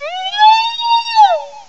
cry_not_aromatisse.aif